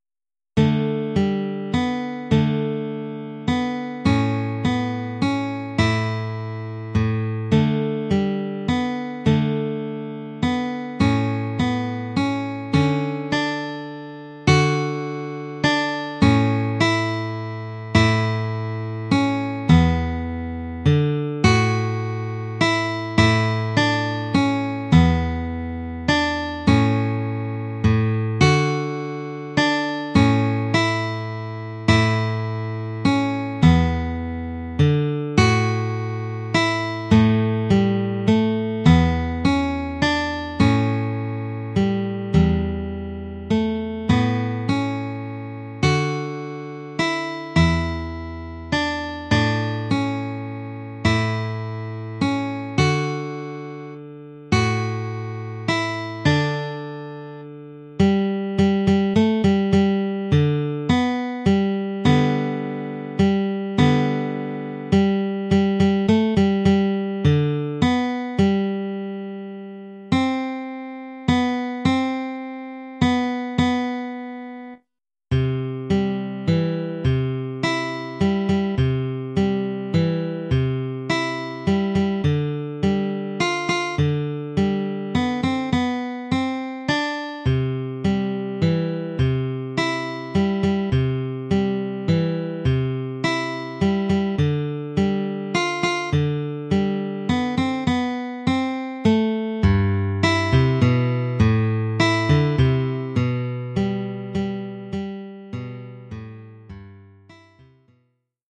1 titre, guitare : partie de guitare
Oeuvre pour guitare solo.